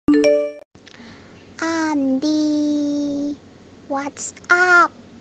Nada notifikasi Andi WhatsApp
Notification icon Nada dering WA sebut nama suara Google
Kategori: Nada dering
nada-notifikasi-andi-whatsapp-id-www_tiengdong_com.mp3